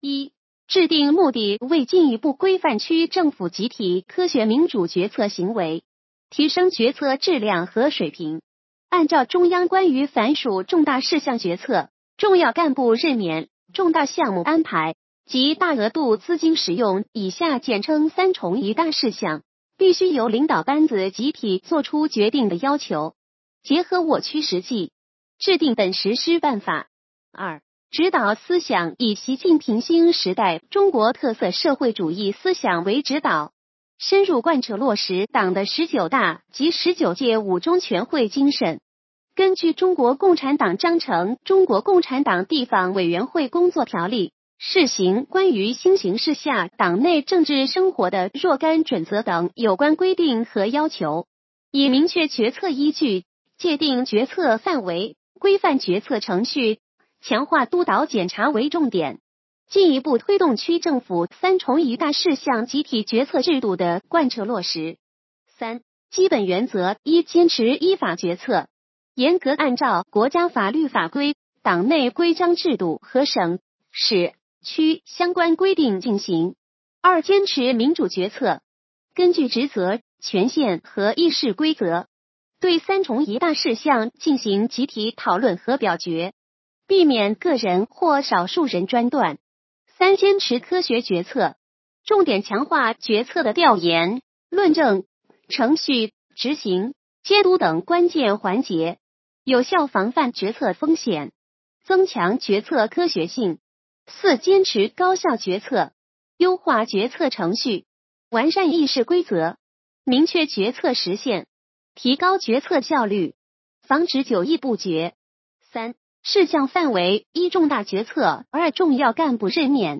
语音解读